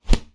Close Combat Attack Sound 16.wav